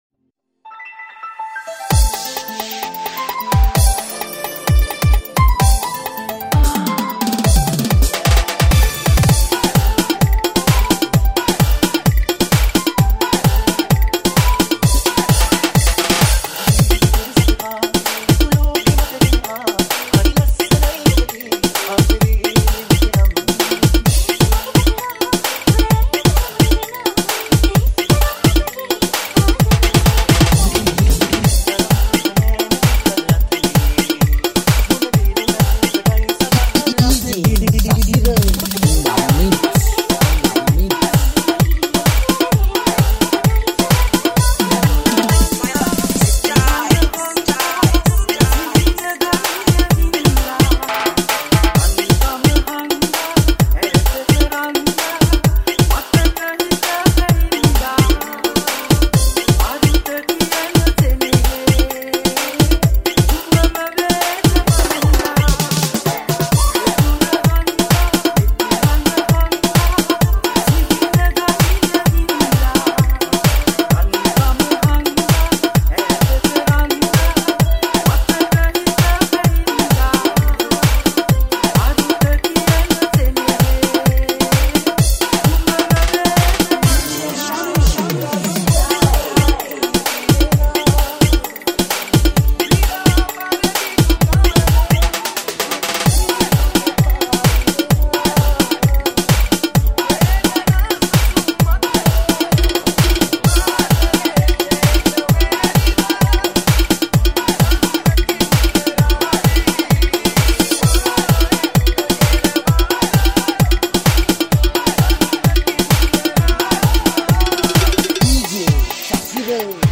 High quality Sri Lankan remix MP3 (6.2).